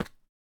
twodcraft / src / main / resources / sounds / metal / step3.ogg